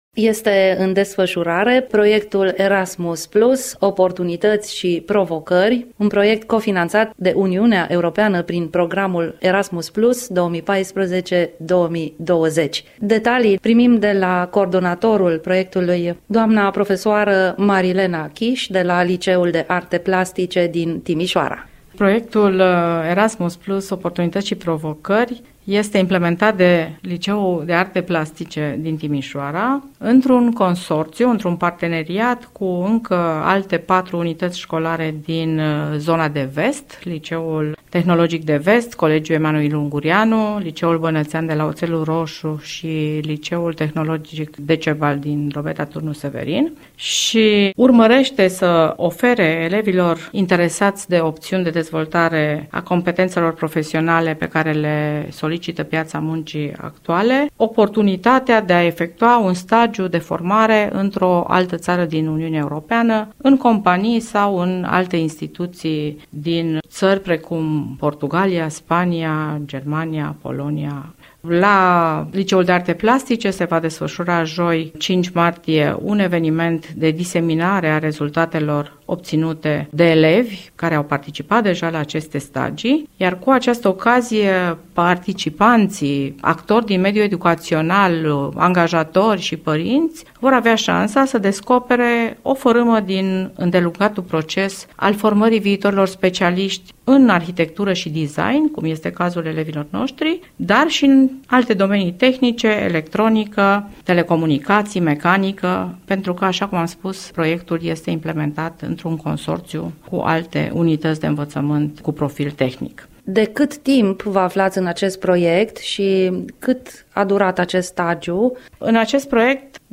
dialogul